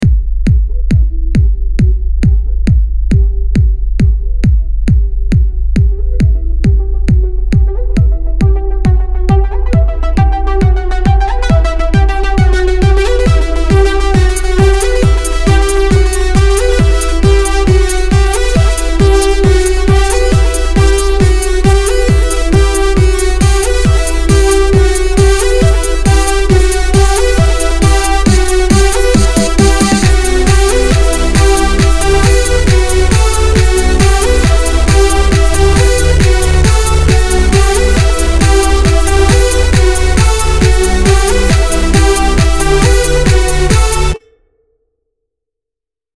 Начало ниче так а потом как вч пошло ... и хня пошла